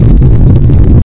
pumpkin_high.ogg